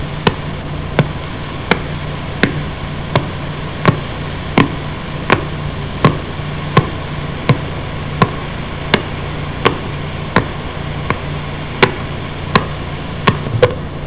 The Sounds of Pulsars
These sounds are from the brightest pulsars in the sky, recorded using some of the largest radiotelescopes in the world.